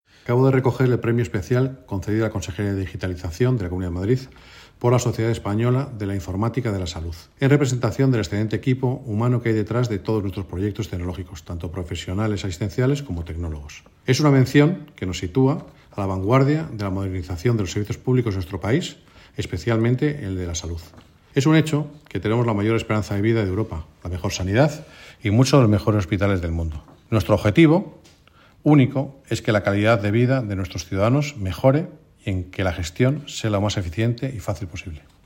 Declaraciones del Consejero Miguel López Valverde '